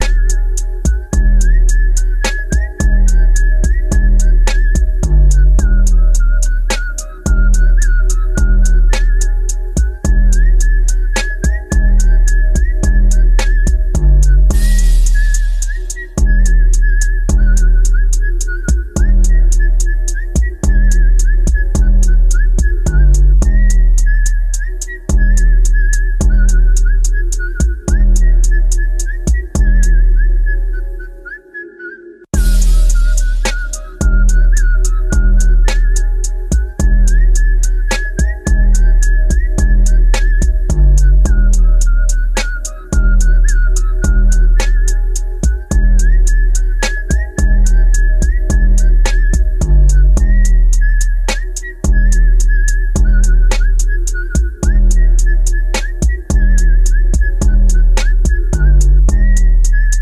Car Audio Setup: Extreme Bass Sound Effects Free Download